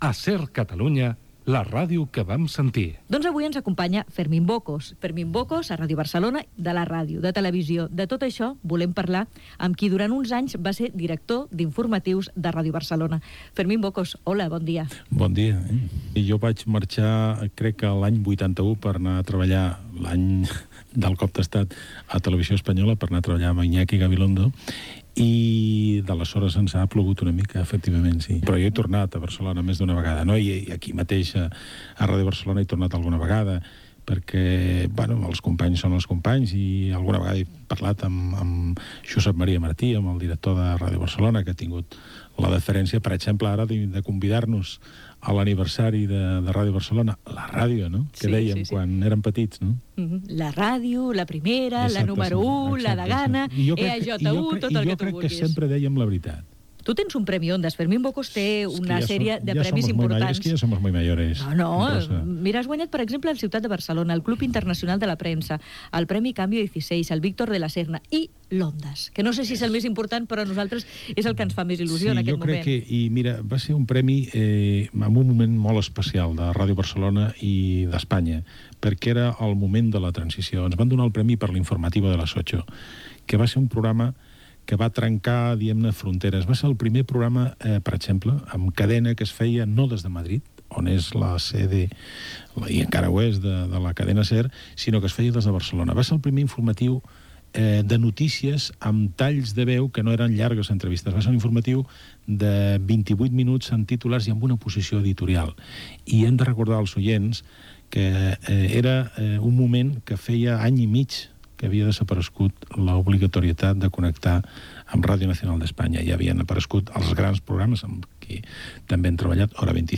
Entrevista a Fermín Bocos sobre la seva trajectòria a Ràdio Barcelona i als mitjans de comunicació i els premis rebuts
Divulgació